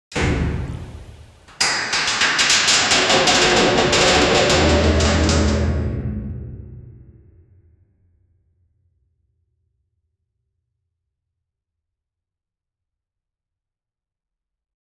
Compact and satisfying, like sealing or packing energy. 0:01 Gigantic metal door falling to seal off a cave entrance. 0:15 Game sound effect for a match-3 action.
gigantic-metal-door-falli-pvlcbw62.wav